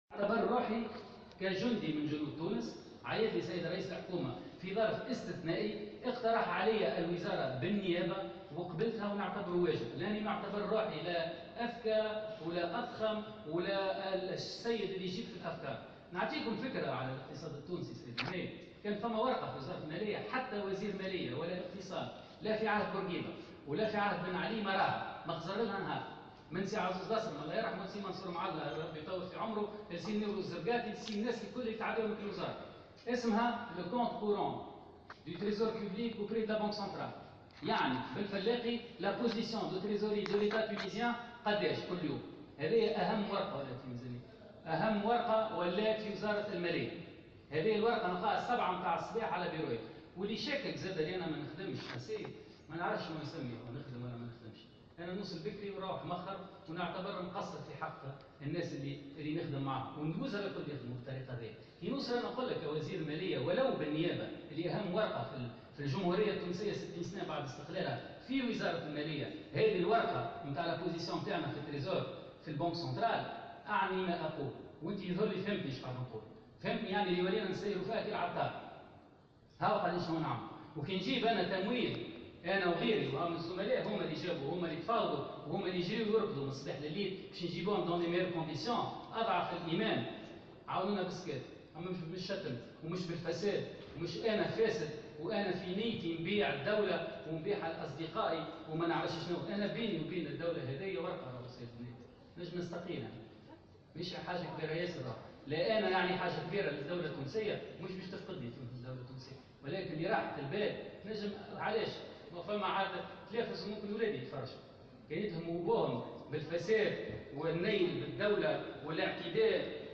قال وزير المالية بالنيابة محمد الفاضل عبد الكافي على اثر اتهامات الفساد التي وجهها بعض نواب المعارضة له اليوم الخميس في تصريح لمراسلة الجوهرة "اف ام" إنه يعتبر نفسه جندي من جنود تونس وقبل بمنصب وزير المالية بالنيابة الذي عرضه عليه رئيس الحكومة من منطلق رغبته في خدمة تونس حسب قوله